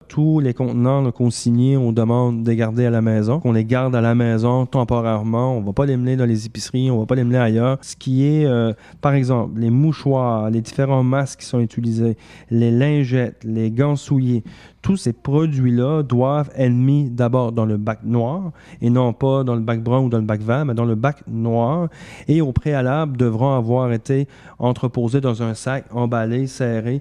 Le maire des Îles, Jonathan Lapierre
Le maire des Iles, Jonathan Lapierre, hausse le ton et implore la population de respecter les règles d’isolement mises en place par le gouvernement du Québec pour éviter une propagation de la COVID-19 aux Iles.
En point de presse vendredi, il souligne que bien que le message semble passer dans une partie de la population, l’achalandage demeure problématique dans plusieurs commerces de l’archipel.